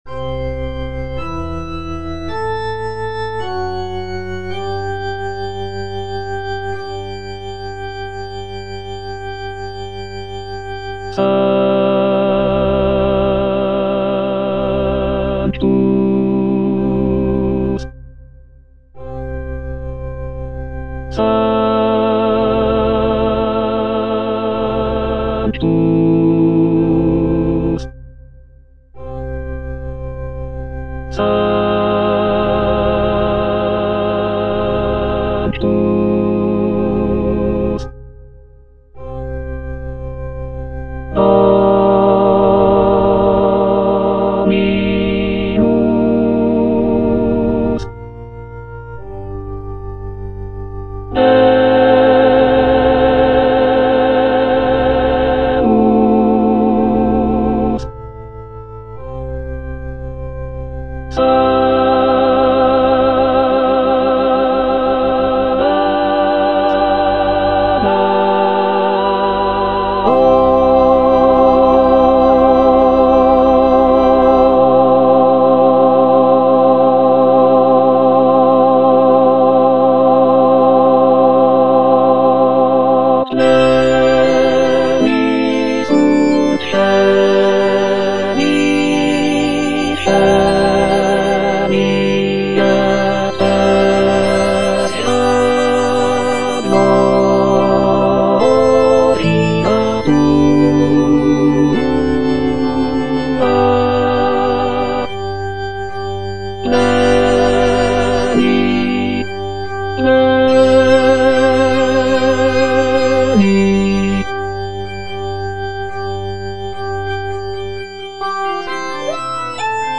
sacred choral work